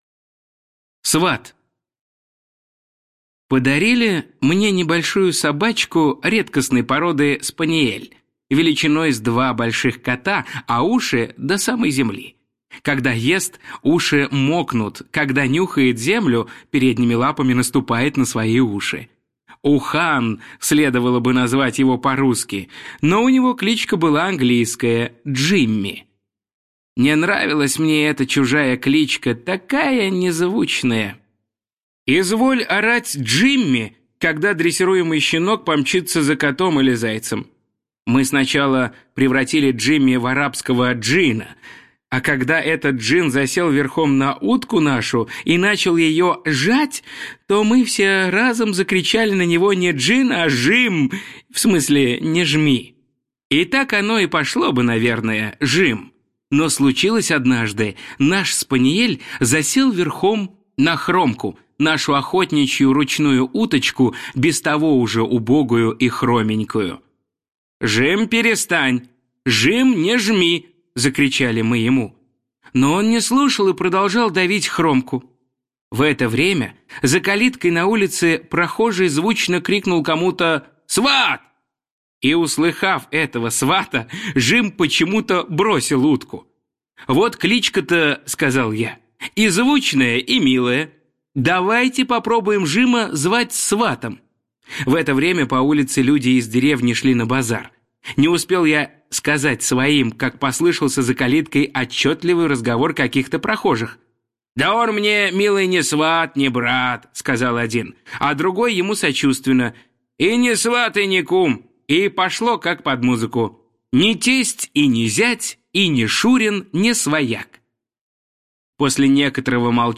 Сват - аудио рассказ Пришвина М.М. В рассказах М.Пришвина о людях и животных есть прекрасные описания лесов и полей, рек и озёр.